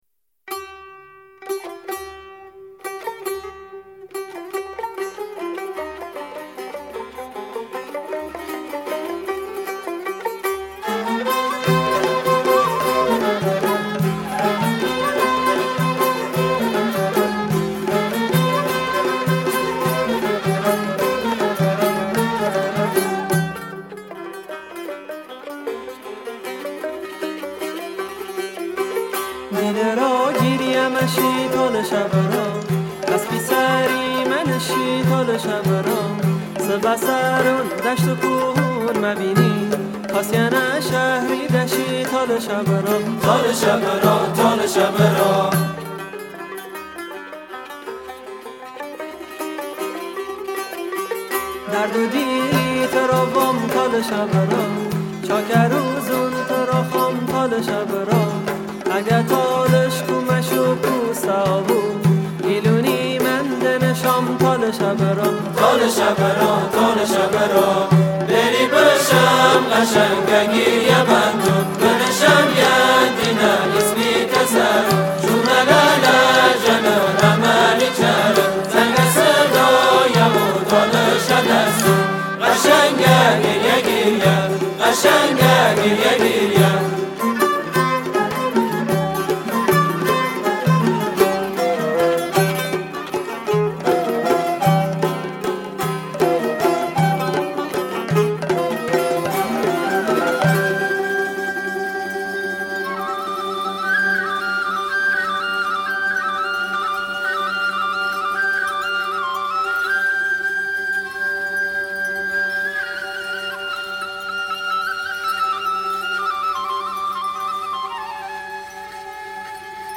موسیقی تالشی